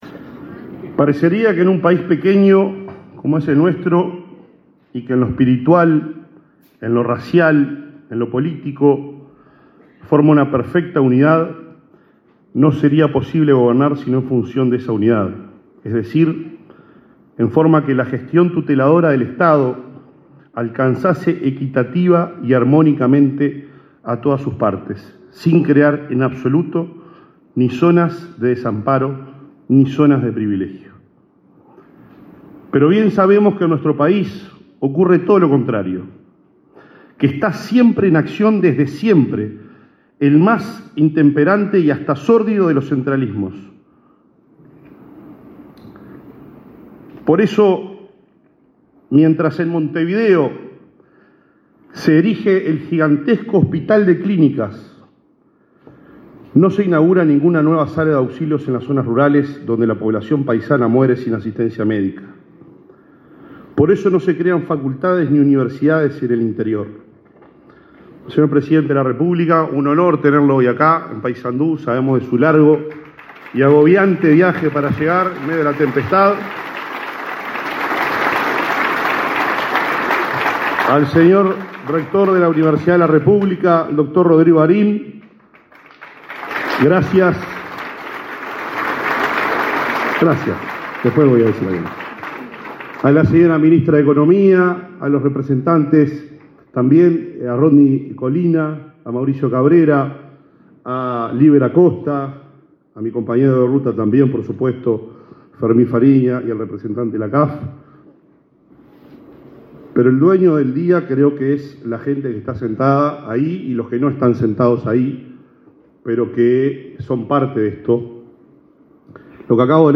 Participaron del evento la ministra Azucena Arbeleche y el intendente Nicolás Olivera.